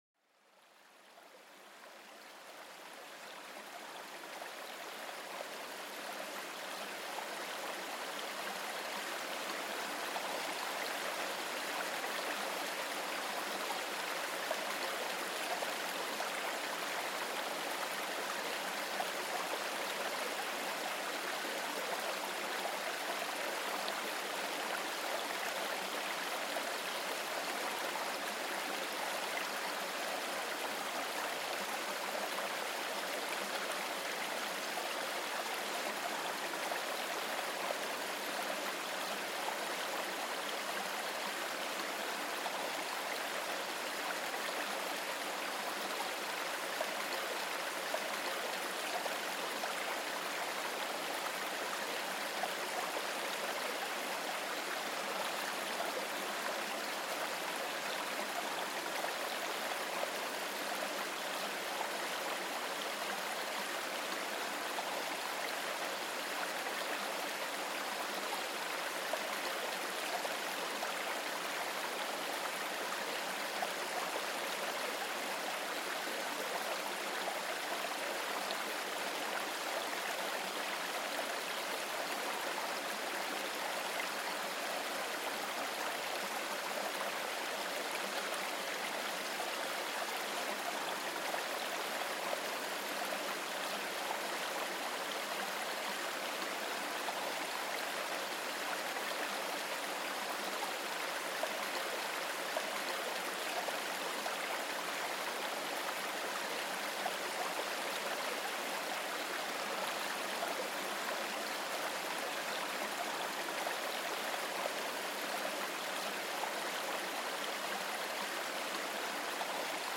Sigue el tranquilo curso de un río, donde el agua fluyente teje una melodía calmante que habla directamente al alma. Explora cómo el sonido continuo del agua puede promover la relajación, despertar los sentidos y fomentar una meditación profunda.